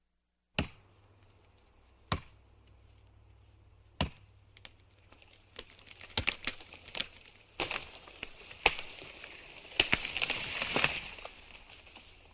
Tiếng Chặt cây và tiếng cây Đỗ xuống
Thể loại: Tiếng động
Description: Tiếng đốn cây, tiếng hạ cây, tiếng ngã cây, tiếng cưa cây, tiếng thân gỗ sập, tiếng gãy đổ, âm thanh “cộc… cộc… rắc!” khi lưỡi rìu hoặc cưa va mạnh vào thân gỗ, tiếp theo là tiếng “rào… ầm!” vang dội khi thân cây nghiêng và ngã xuống mặt đất.
tieng-chat-cay-va-tieng-cay-do-xuong-www_tiengdong_com.mp3